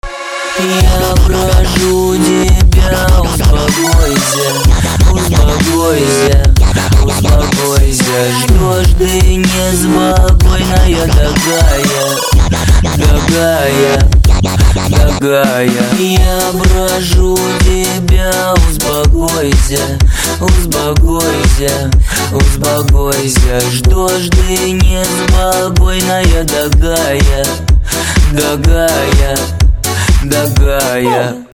• Качество: 320, Stereo
громкие
Electronic